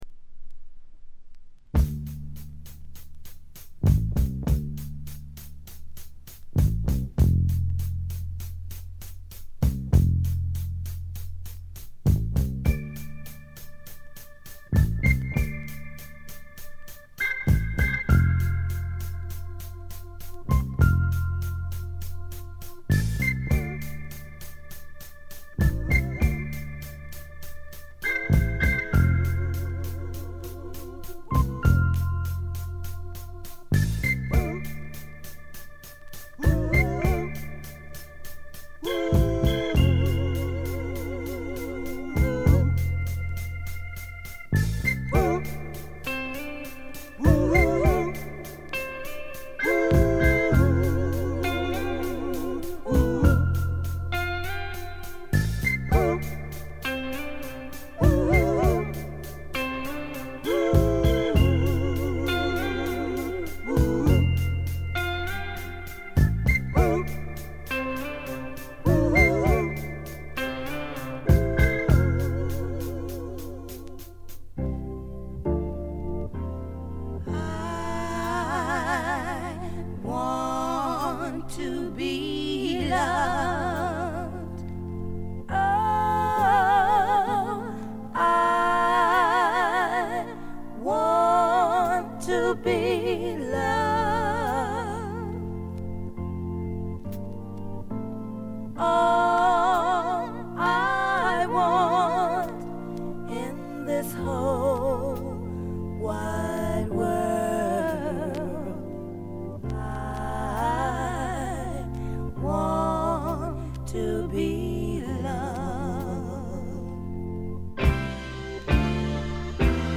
メンフィス産の男女4人組のソウル・ヴォーカル・グループ。
4人全員がリードをとる迫力は圧巻です。
ディープな南部ソウル名作中の名作です！
試聴曲は現品からの取り込み音源です。